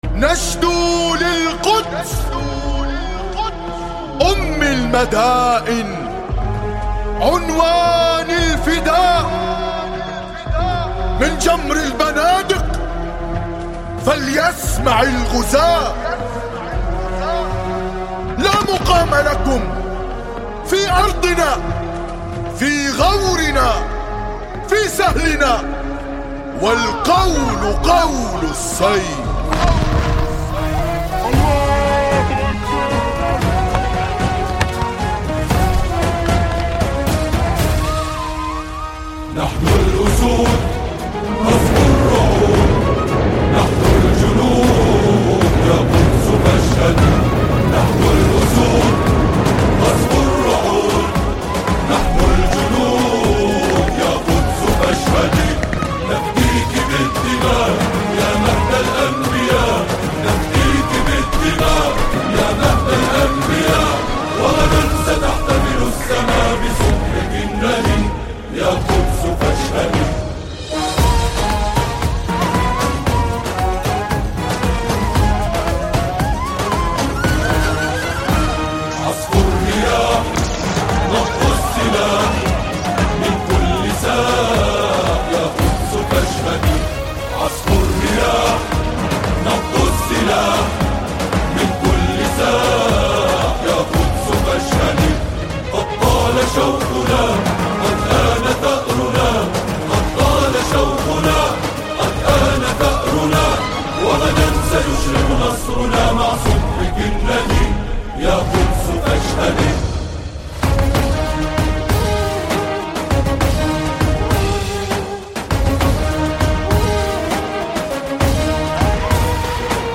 سرودهای ویژه مقاومت فلسطین
سرود